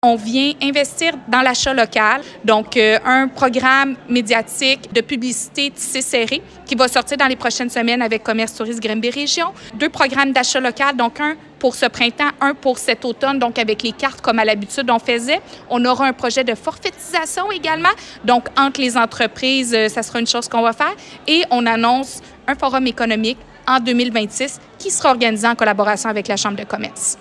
C’est en présence de plus de 230 invitées et invités issus des milieux économiques, sociaux, communautaires, de la santé, de même que d’organismes partenaires de la Ville, que s’est tenue la 3e édition du souper de la mairesse de Granby le jeudi 3 avril dernier.
Comme nouveautés cette année, écoutons la mairesse de Granby, Julie Bourdon.